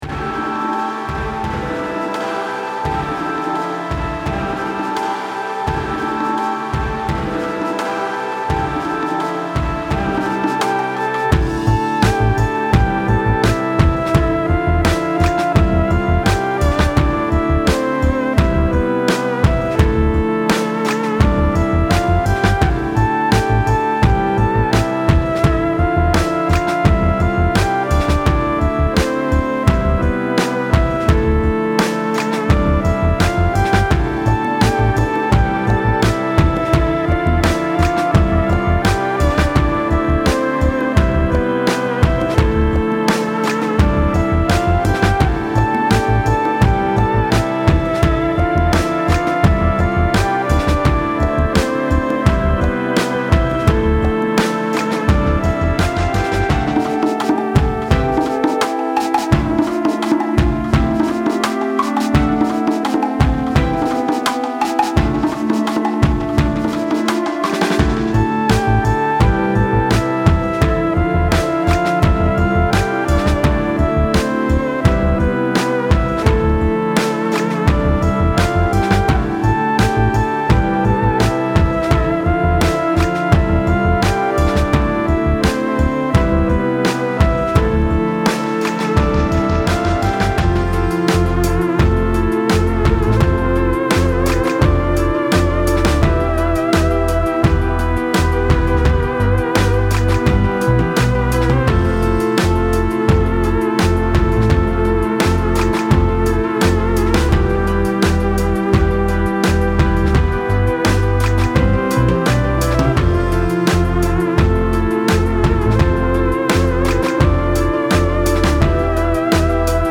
Some keywords that describe the project are 'ambient' 'psychedelic' and 'chilled', though if you listen to the music you can make your own judgement.
Electronic